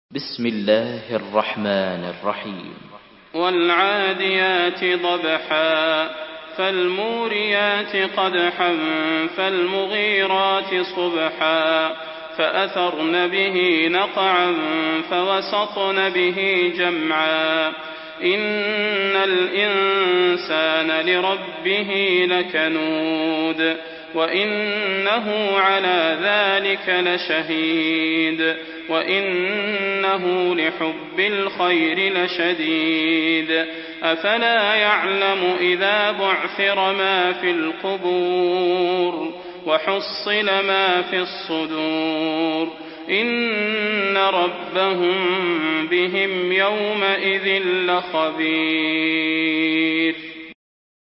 سورة العاديات MP3 بصوت صلاح البدير برواية حفص
مرتل